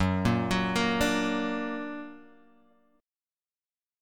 Gb+ chord